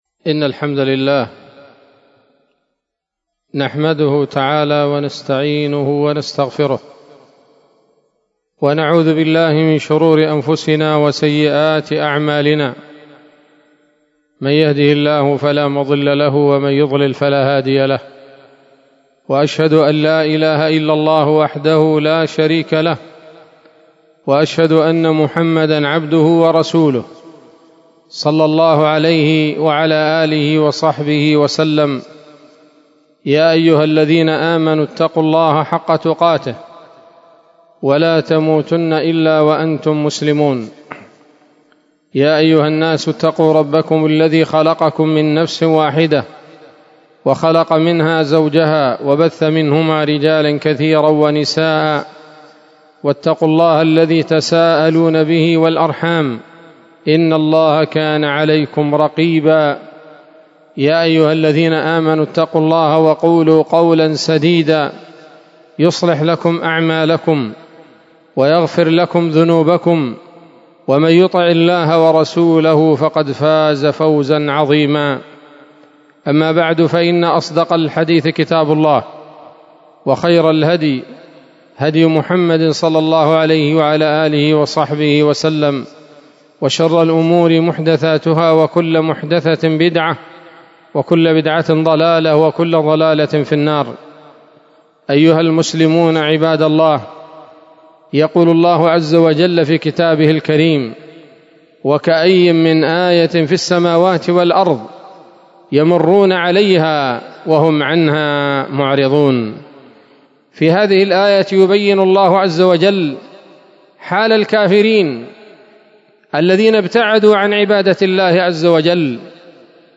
خطبة جمعة بعنوان: (( التأمل في آيات الله )) 13 شوال 1446 هـ، دار الحديث السلفية بصلاح الدين